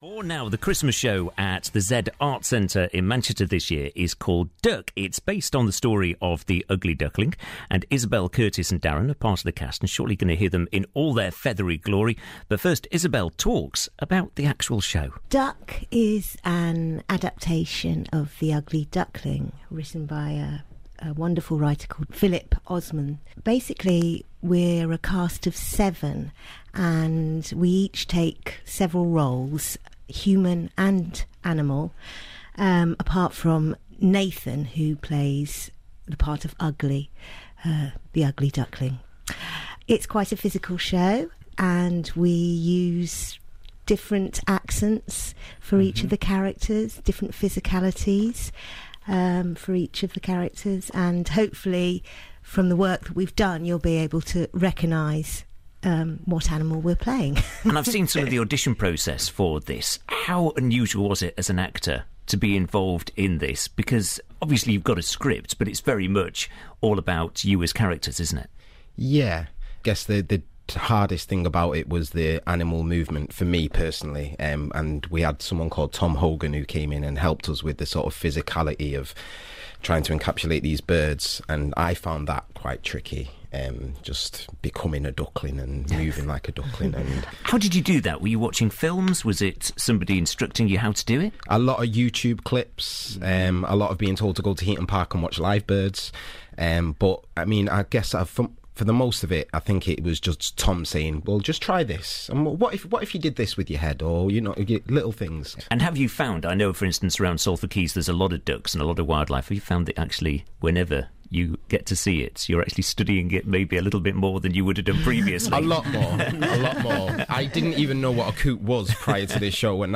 Duck! Cast Interviewed on BBC Radio Manchester